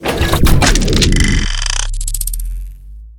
laserin.ogg